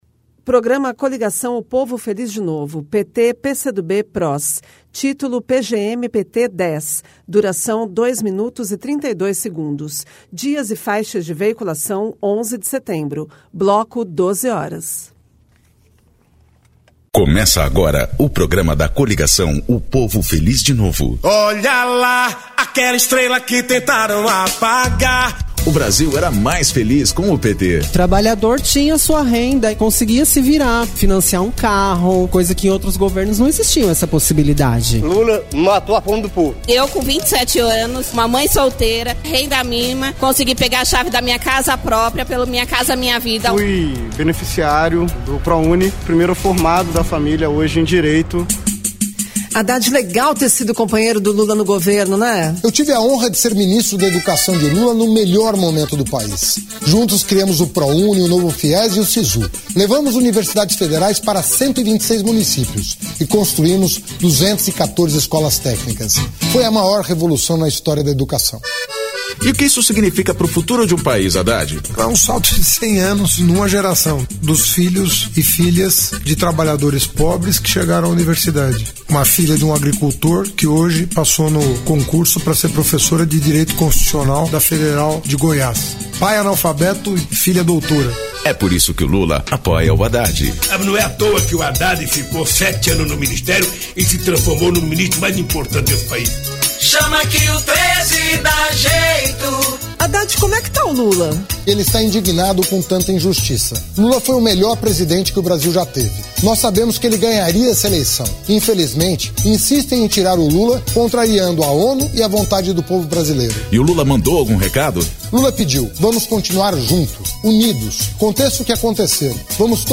Descrição Programa de rádio da campanha de 2018 (edição 10) - 1° turno